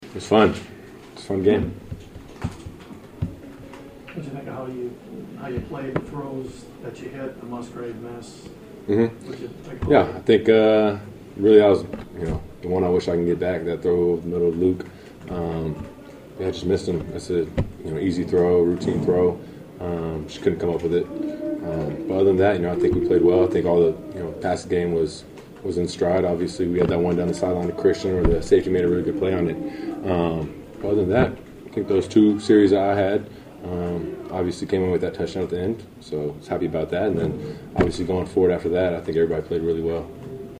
Jordan Love joins happy locker room.